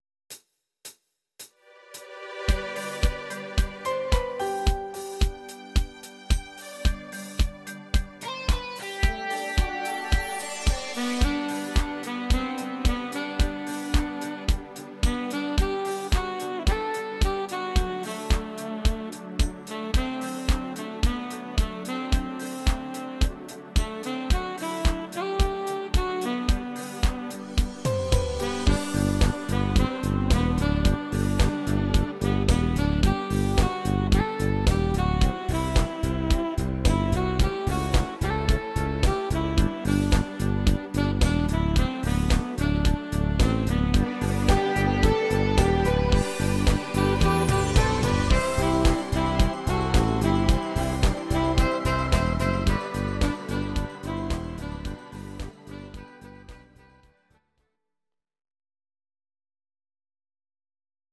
Audio Recordings based on Midi-files
Pop, German, 2000s